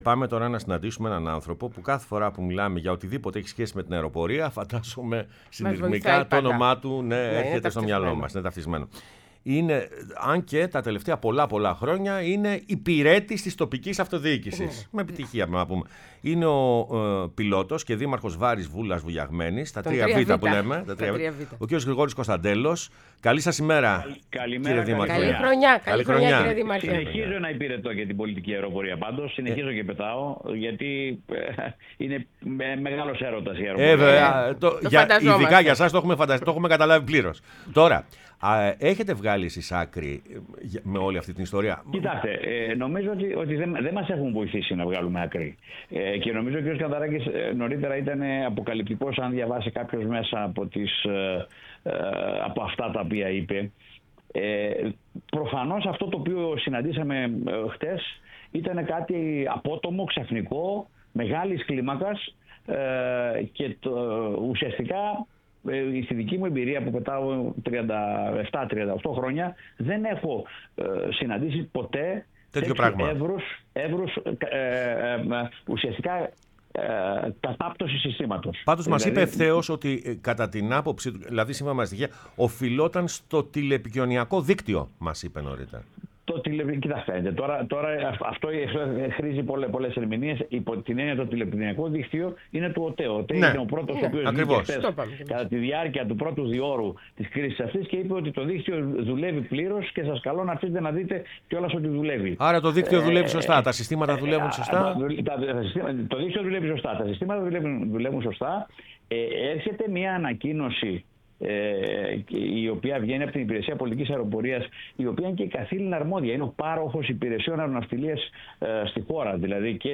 Ο Γρηγόρης Κωνσταντέλλος, πιλότος και δήμαρχος Βάρης Βούλας Βουλιαγμένης, μίλησε στην εκπομπή “Πρωινές Διαδρομές”